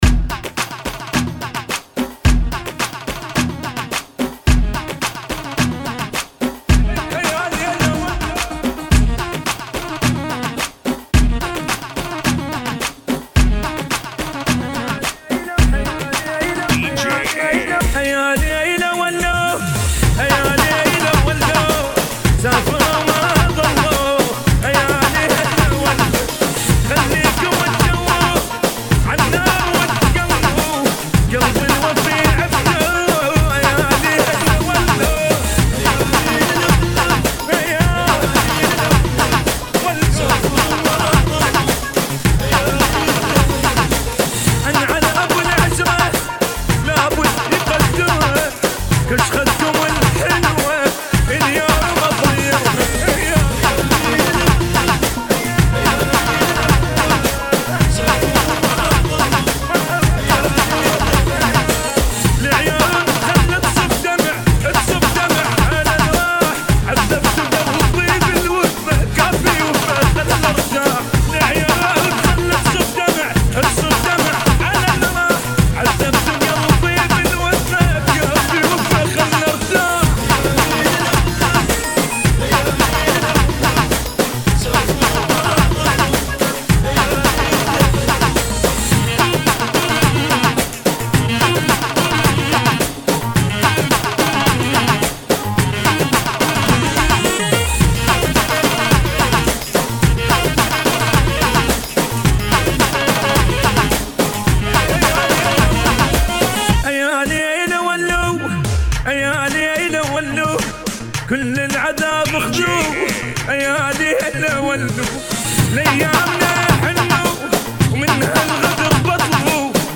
108 BBM